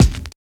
71 KICK 3.wav